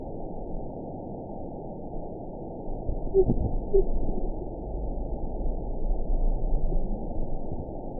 event 922642 date 02/14/25 time 02:53:41 GMT (2 months, 2 weeks ago) score 5.56 location TSS-AB10 detected by nrw target species NRW annotations +NRW Spectrogram: Frequency (kHz) vs. Time (s) audio not available .wav